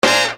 Hit 002.wav